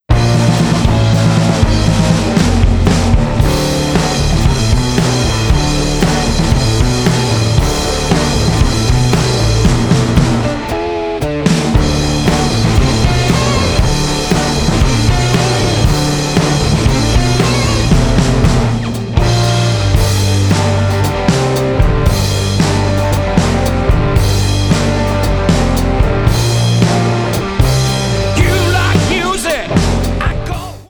Voix, Guitares
Batterie